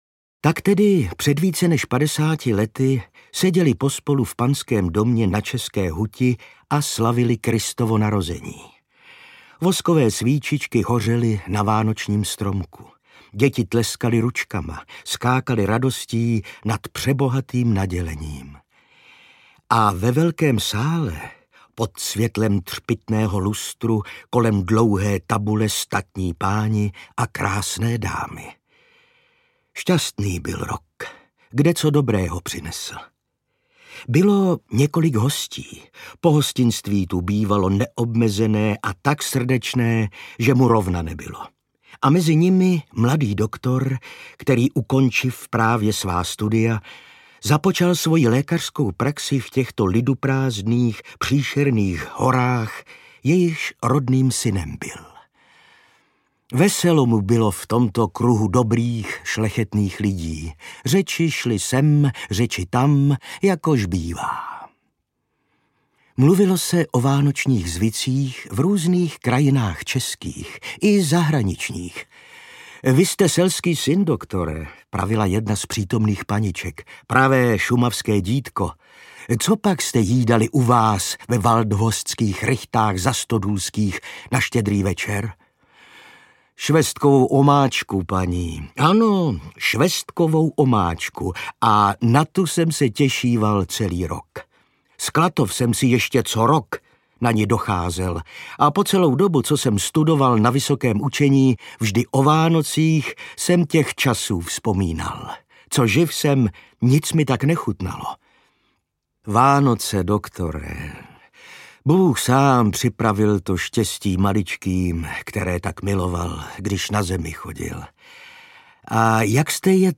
Vánoční povídky ze Šumavy audiokniha
Ukázka z knihy
• InterpretIvan Řezáč